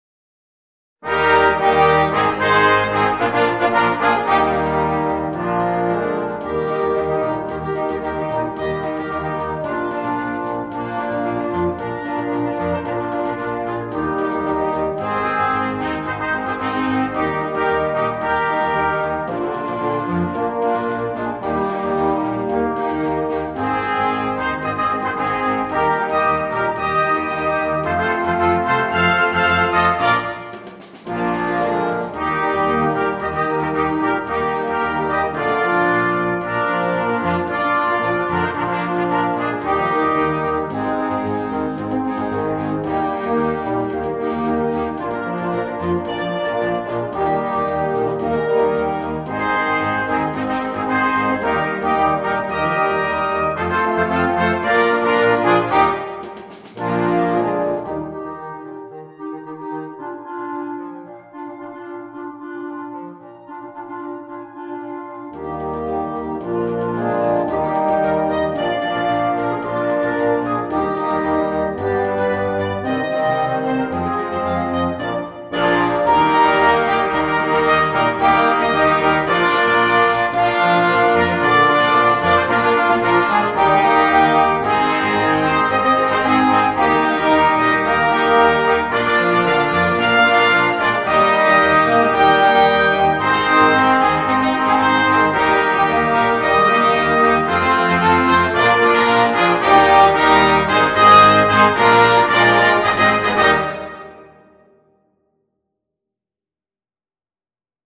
Full Band
without solo instrument
Entertainment